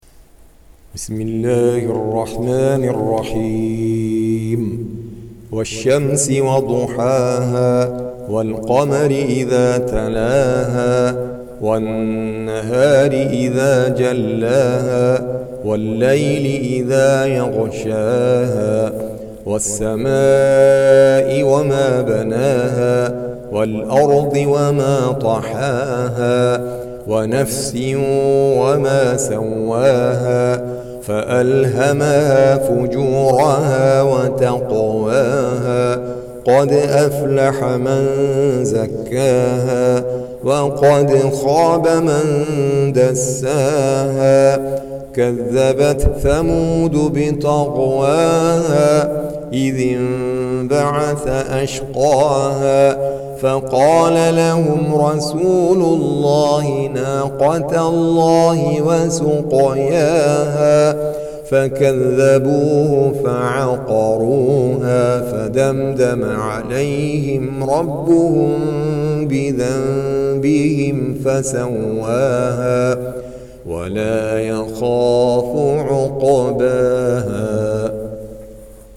Surah Sequence تتابع السورة Download Surah حمّل السورة Reciting Murattalah Audio for 91. Surah Ash-Shams سورة الشمس N.B *Surah Includes Al-Basmalah Reciters Sequents تتابع التلاوات Reciters Repeats تكرار التلاوات